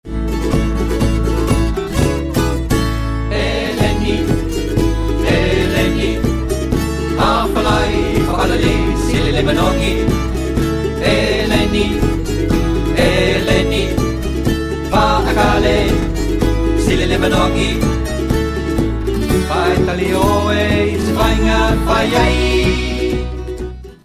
Evenmin nemen we stelling tegen dierenleed met het demonstreren of aanleren van een Samoaans danslied over geïmporteerde "vis uit blik", wat tegenwoordig op veel eilanden populairder is dan verse vis die ze bij wijze van spreken voor de deur kunnen vangen. In het liedje Eleni (